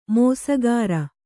♪ mōsagāra